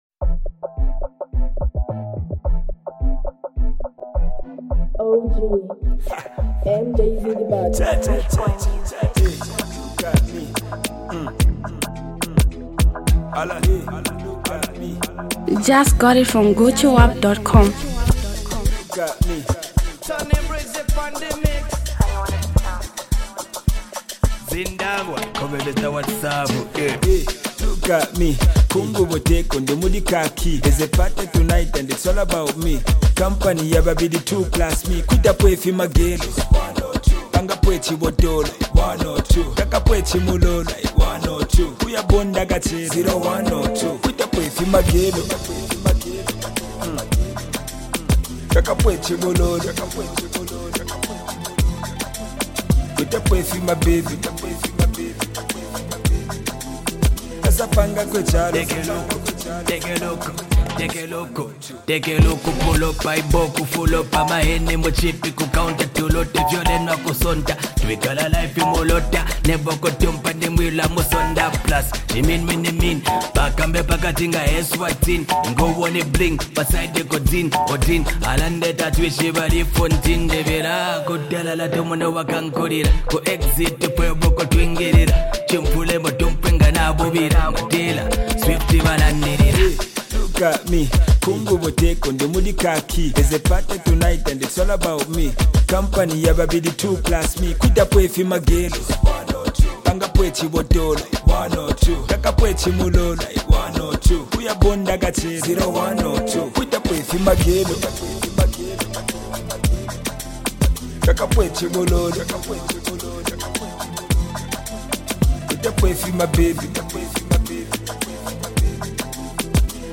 Zambian Mp3 Music
street buzzing anthem